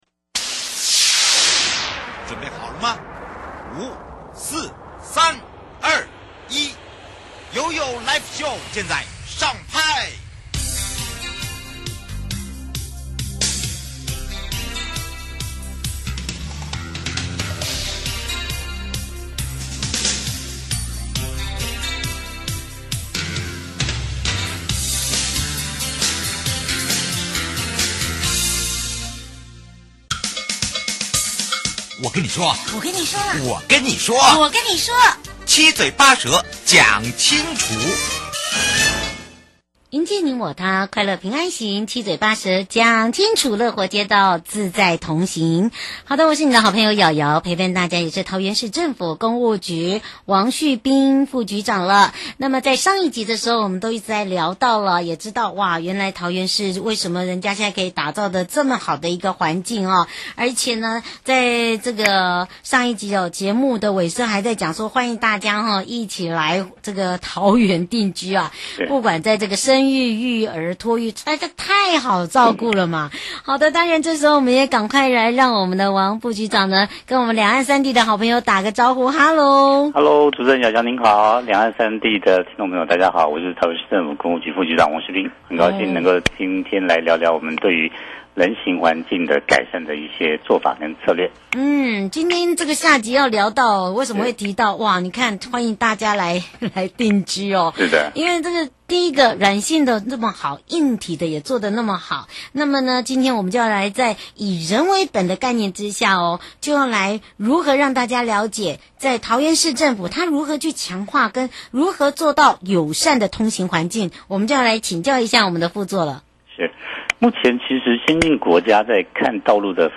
另外想要請教桃園市政府，對於前瞻—提升道路品質計畫的推動感想 節目內容： 桃園市政府工務局王旭斌副局長(下集)